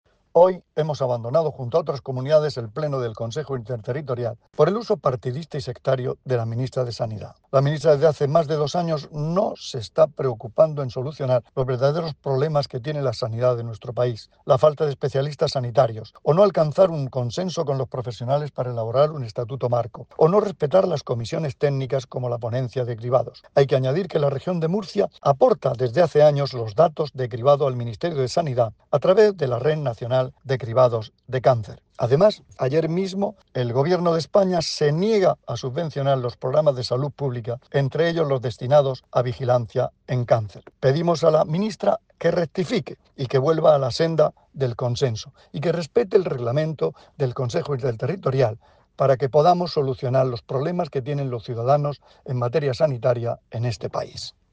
Declaraciones del consejero de Salud, Juan José Pedreño, sobre la reunión del Consejo Interterritorial del Sistema Nacional de Salud celebrada hoy.